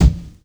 • Loud Bass Drum B Key 570.wav
Royality free kick drum sample tuned to the B note. Loudest frequency: 313Hz
loud-bass-drum-b-key-570-7ky.wav